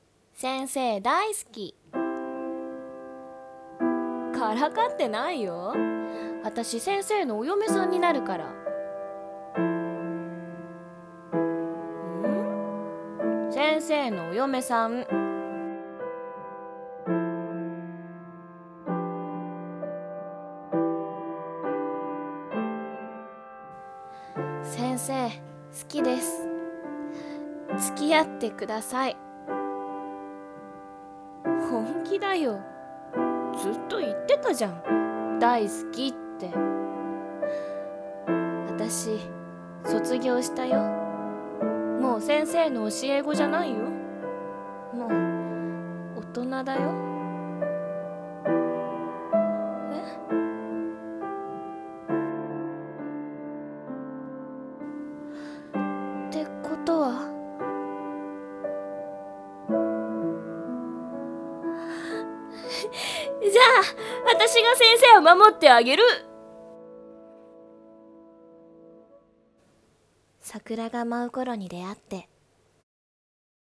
【声劇】桜が舞う頃に、君と。【2人声劇】